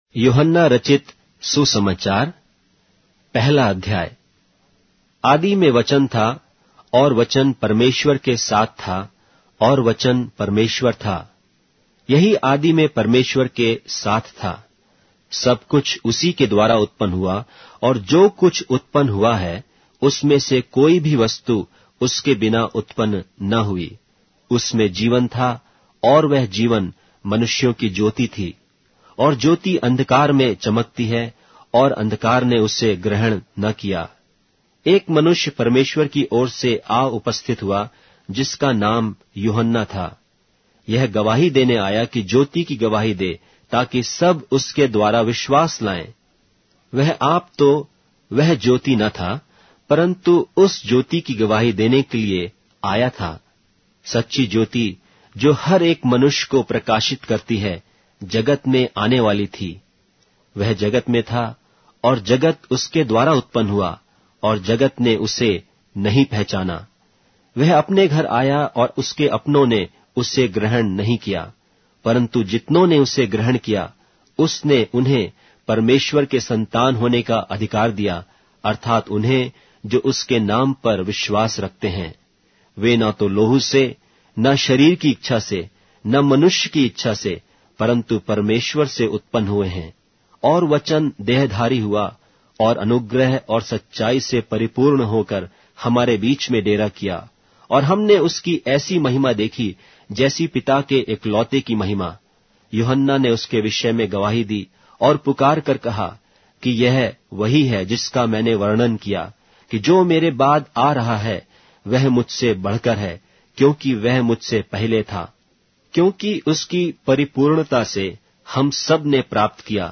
Hindi Audio Bible - John 2 in Hov bible version